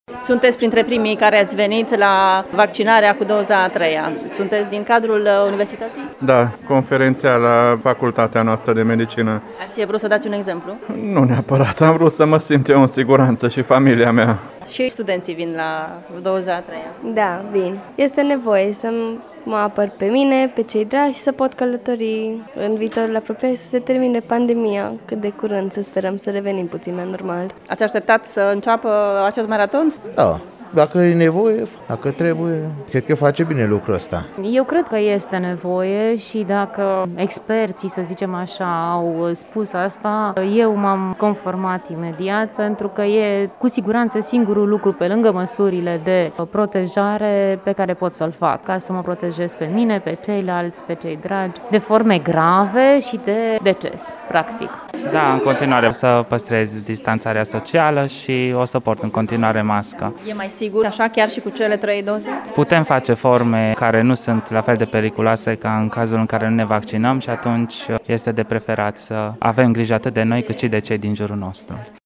Primii care s-au prezentat astăzi la vaccinare cu doza a treia au fost medici și profesori ai Universității de Medicină, dar au fost și studenți: